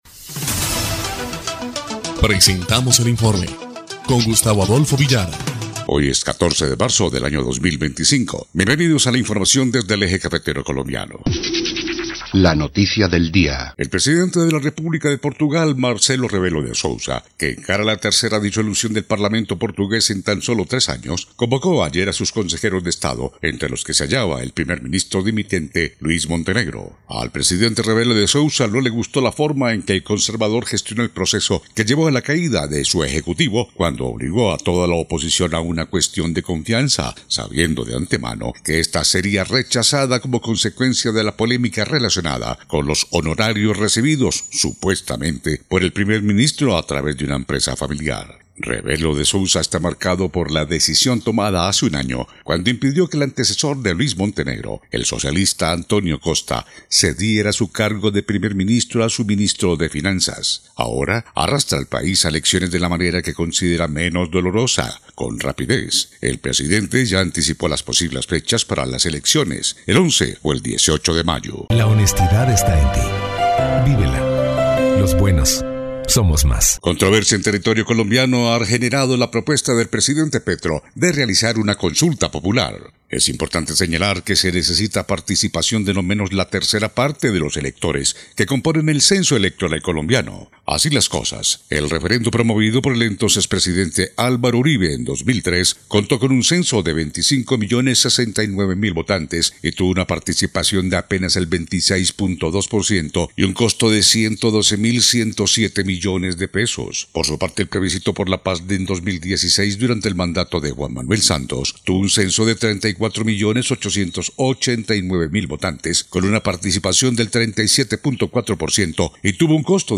EL INFORME 2° Clip de Noticias del 14 de marzo de 2025